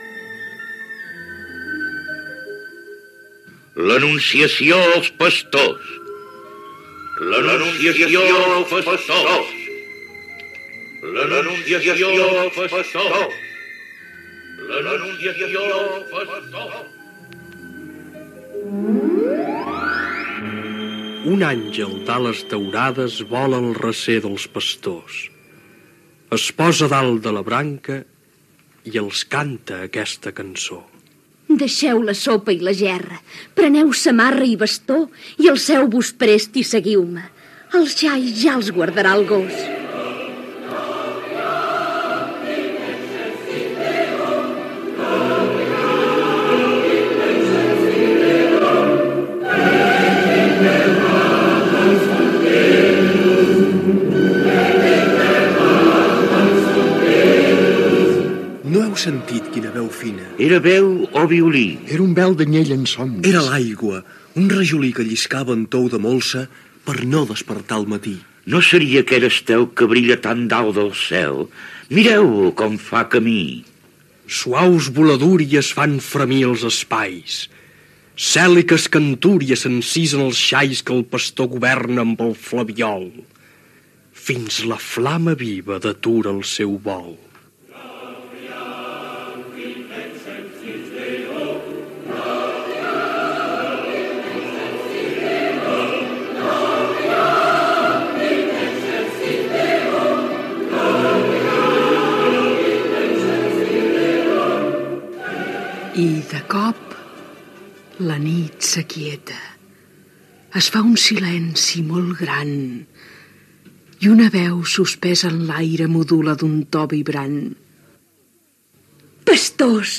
Adaptació radiofònica del "Poema del pesebre" de Joan Alavedra. Primers minuts de l'obra, amb l'anunciació del naixement de Jesús
Ficció